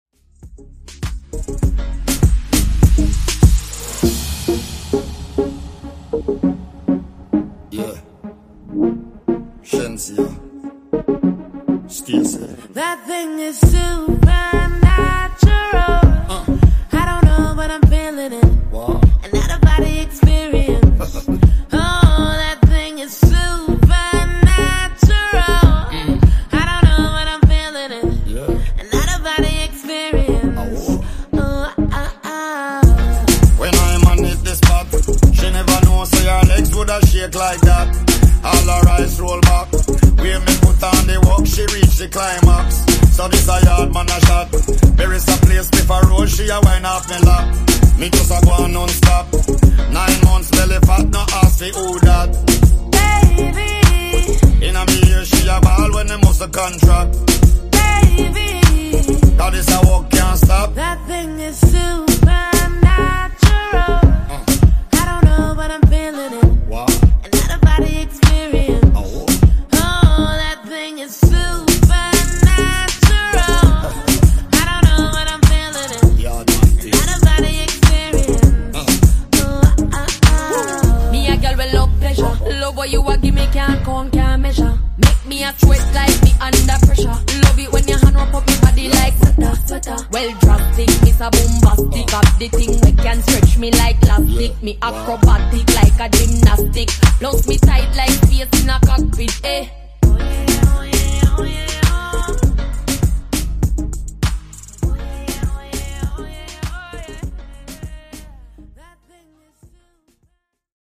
Genre: RE-DRUM Version: Clean BPM: 124 Time